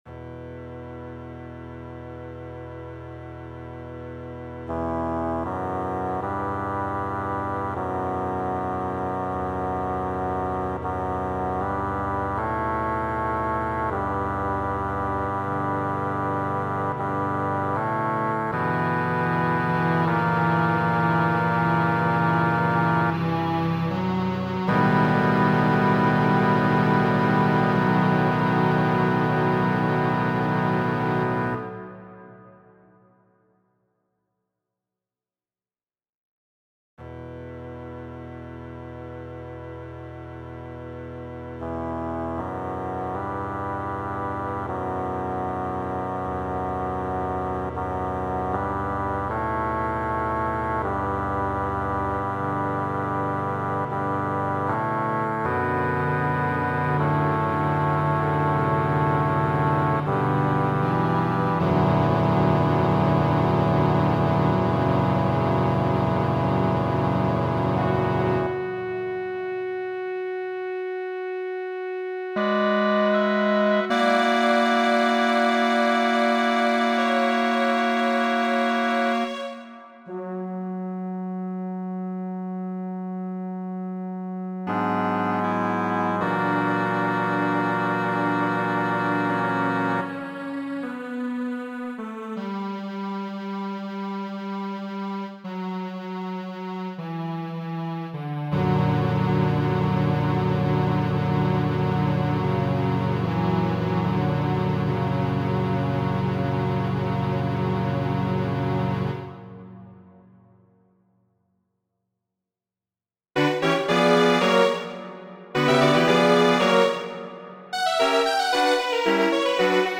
Muziko : Adaĝo (lanteto), unua parto de la Simfonio 6-a Petro Ludoviko Ĉejkovski, verko 74-a, dirita La Patosa pro la enkondukaj taktoj de tiu ĉi parto.